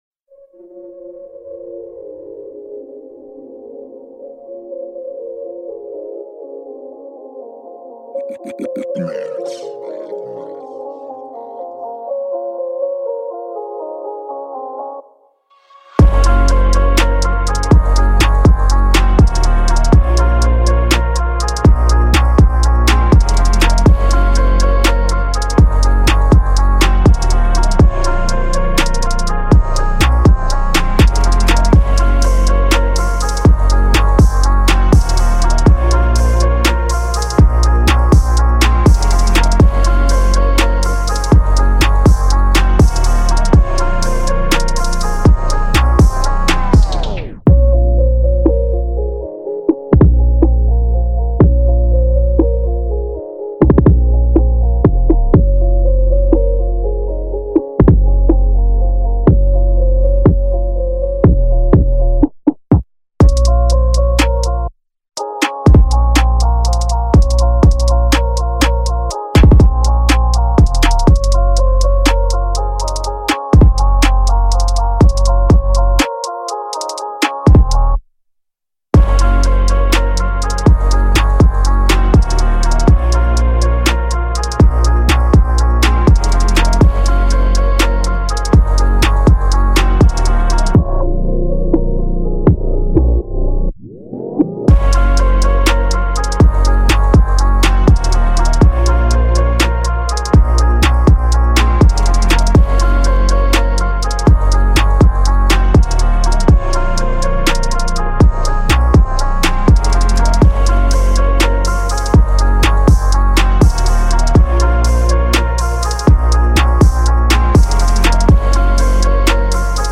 official instrumental
Rap Instrumental